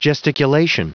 Prononciation du mot gesticulation en anglais (fichier audio)
Prononciation du mot : gesticulation